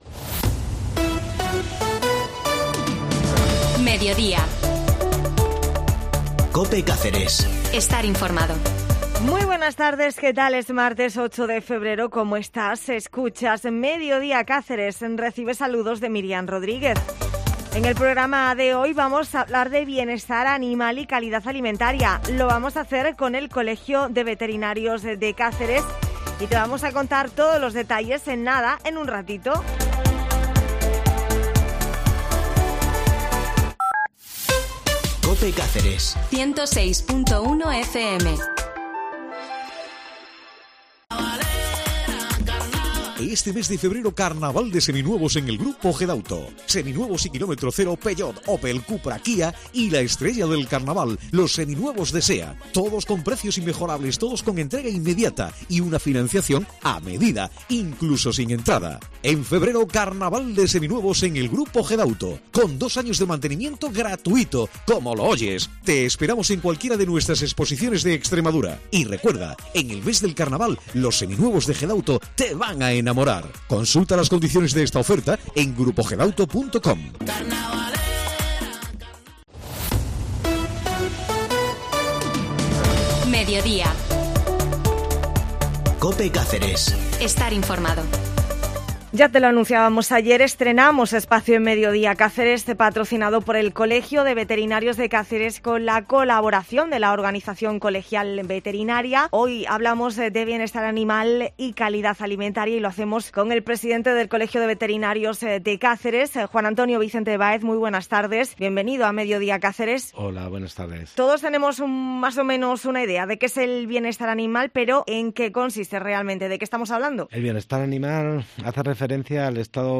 Hablamos de bienestar animal y calidad alimentaria con el Colegio de Veterinarios de Cáceres